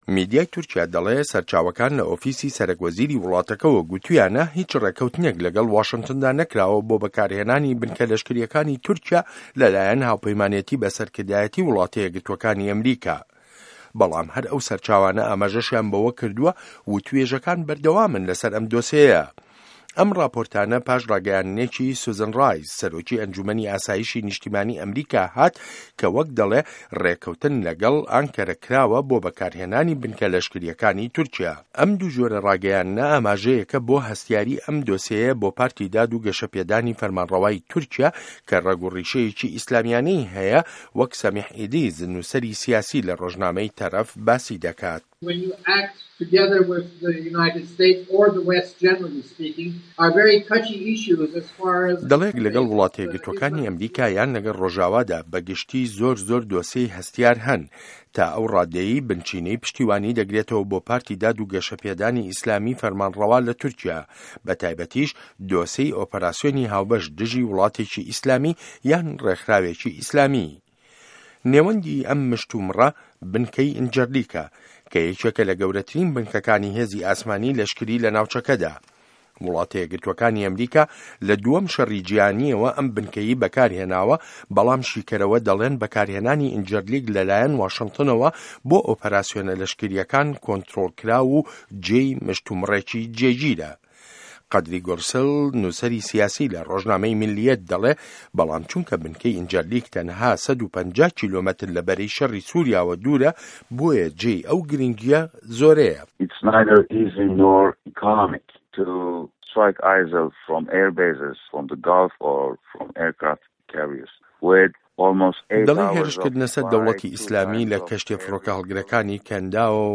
ڕاپـۆرتی تورکـیا - داعش